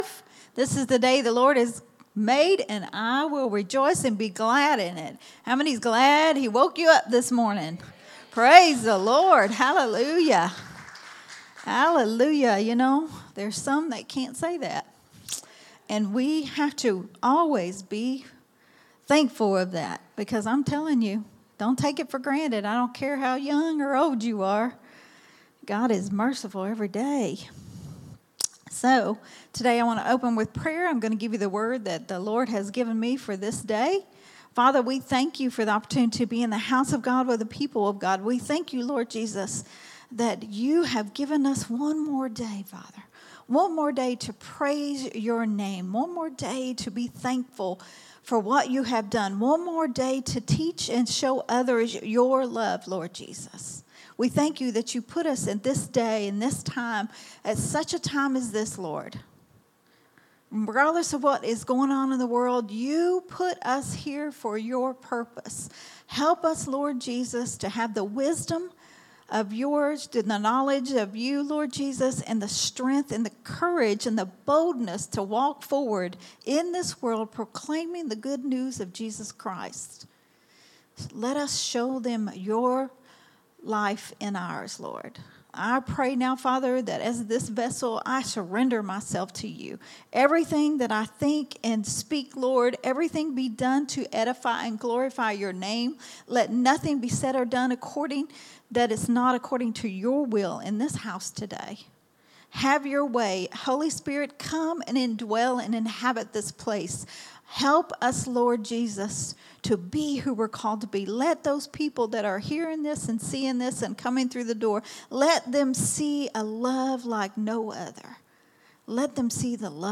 a Sunday Morning Risen Life teaching
recorded at Growth Temple Ministries on Sunday